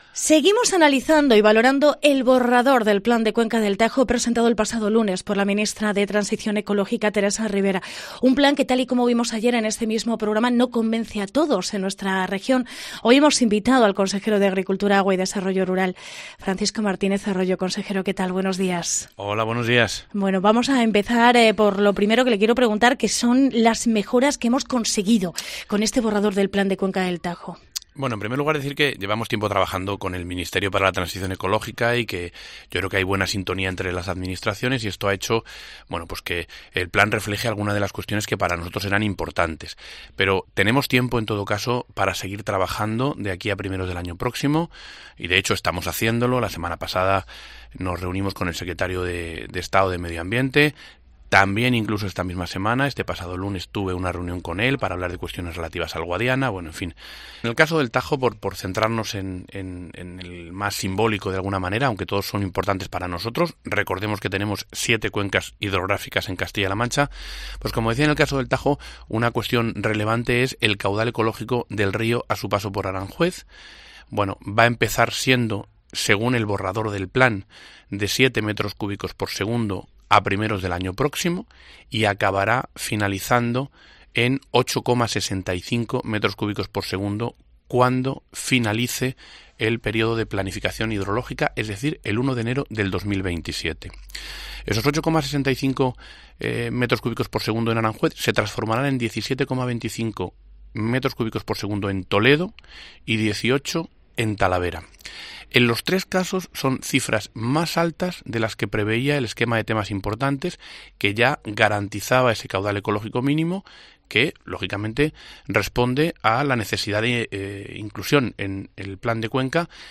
Entrevista Francisco Martínez Arroyo. Consejero de Agricultura